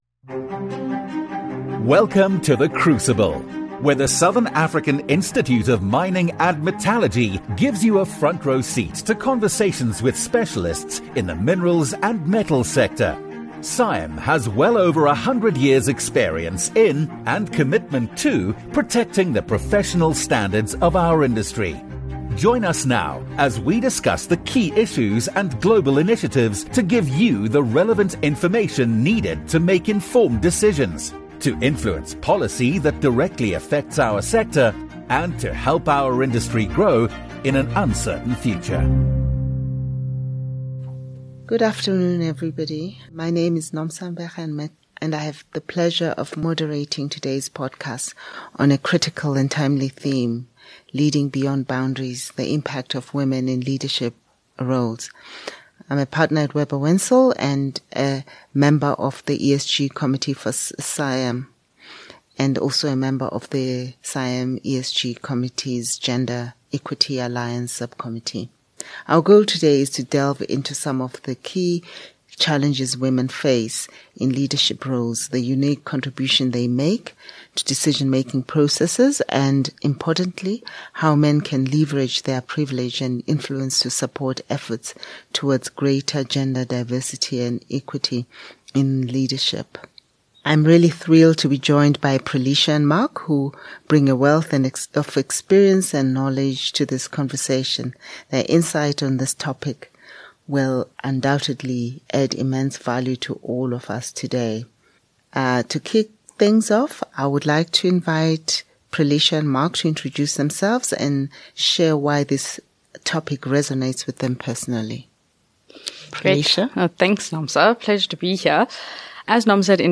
An open conversation on embracing challenges and thoughtful leadership.